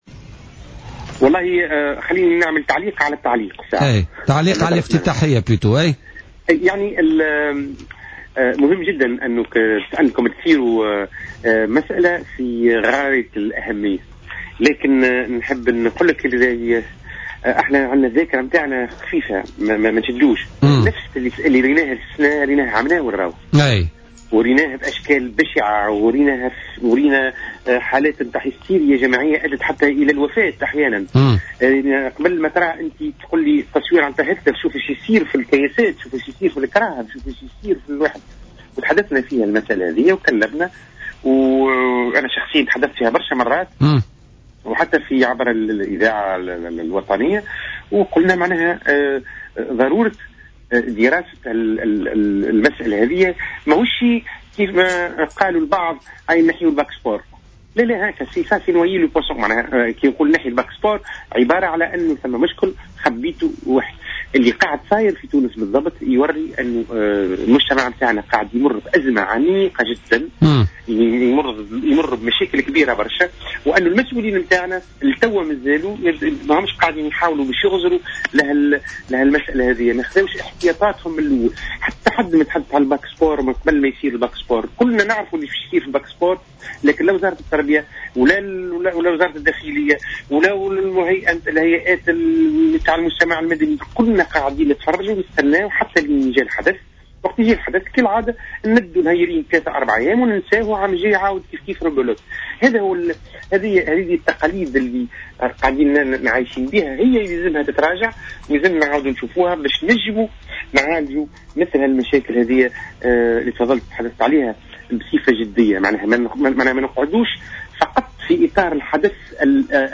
في مداخلة له اليوم في برنامج "بوليتيكا"